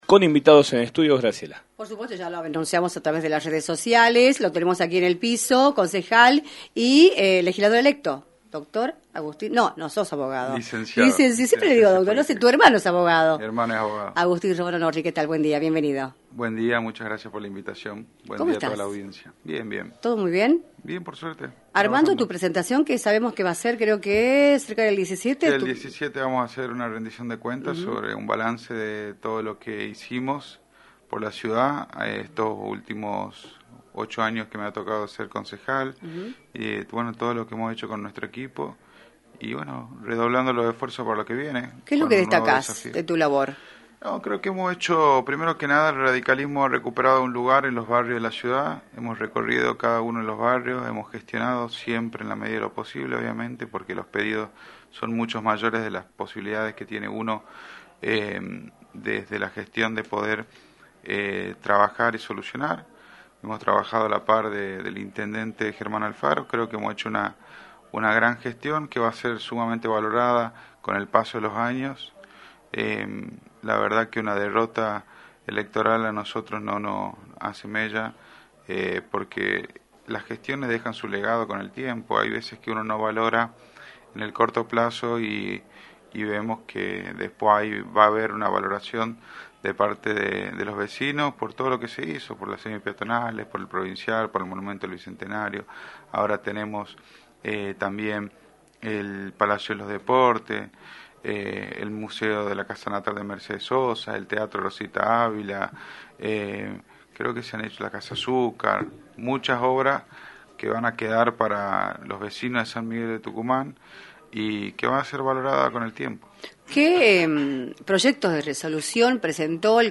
Agustín Romano Norri, Concejal de San Miguel de Tucumán y Legislador electo, analizó en “Libertad de Expresión”, por la 106.9, su gestión como parlamentario de la capital y remarcó cuáles fueron sus sensaciones luego del debate entre candidatos a Presidente realizado en Santiago del Estero.
entrevista